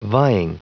Prononciation du mot vying en anglais (fichier audio)
Prononciation du mot : vying